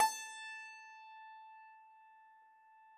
53l-pno17-A3.wav